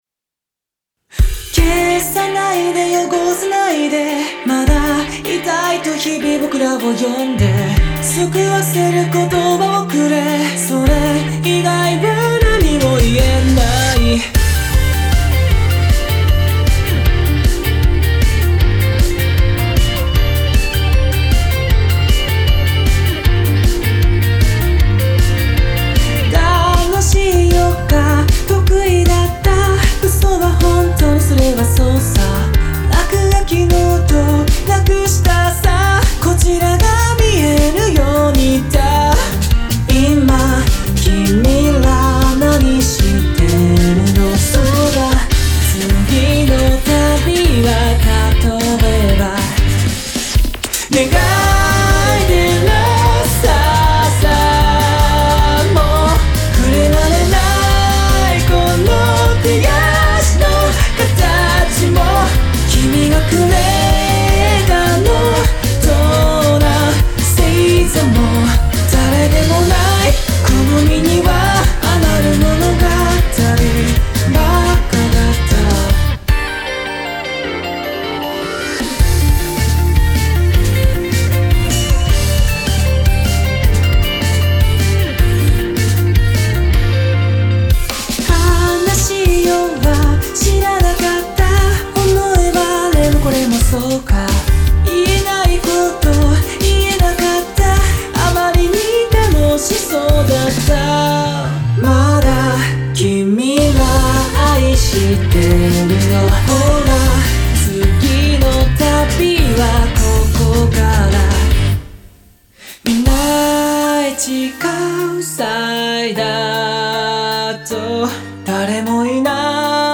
サンプル歌みたミックス
女性Vo